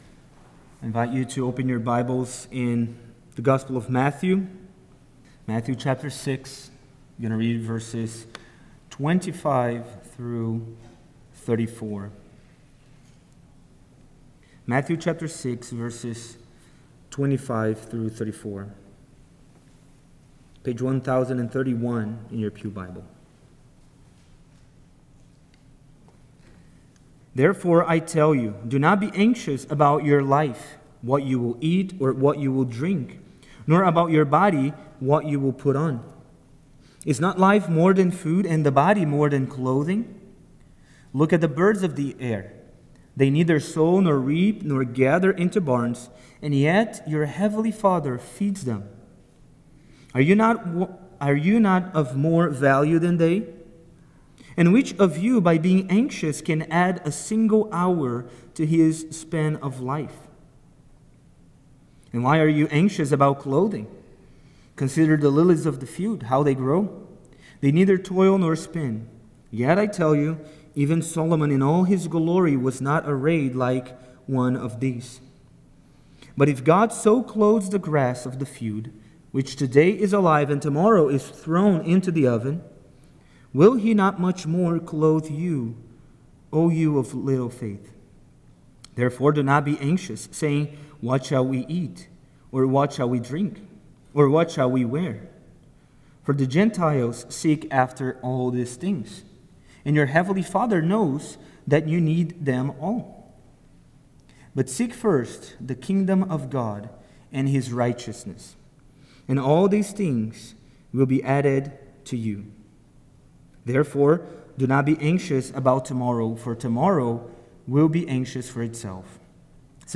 Righteousness Series Various Sermons Book Matthew Watch Listen Save In Matthew 6:25–34, as part of the Sermon on the Mount, Jesus teaches we should be concerned about pleasing God by seeking His kingdom, rather than worrying about our daily needs. This is primarily a heart issue that can only be resolved by coming to Christ.